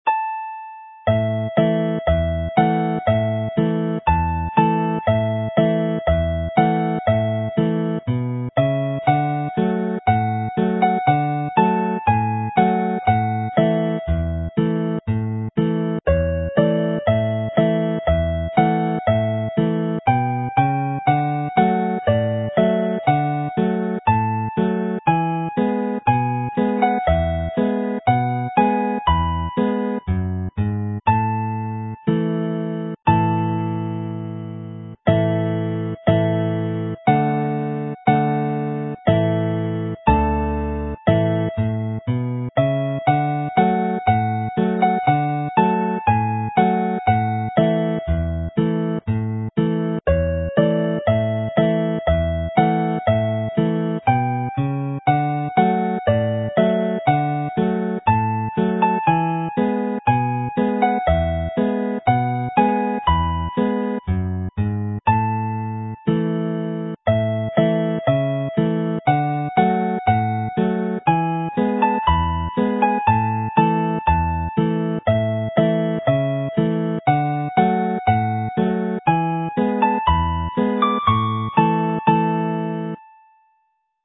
Play slowly